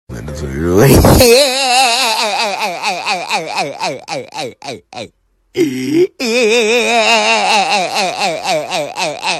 Weird Laugh Sfx